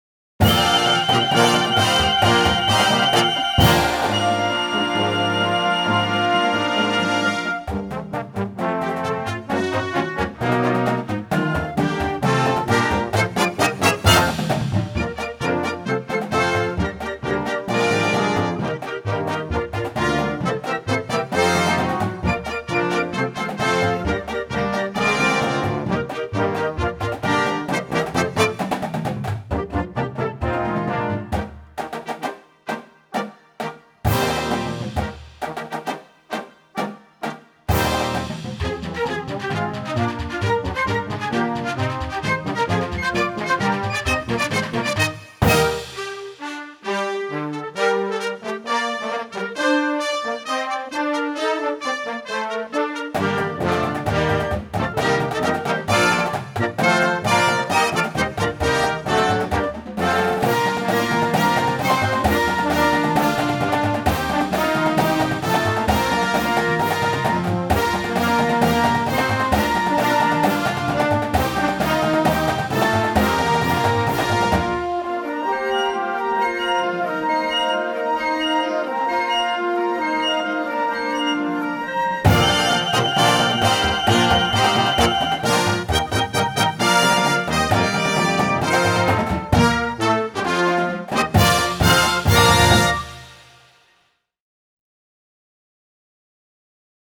Voicing: Christmas Band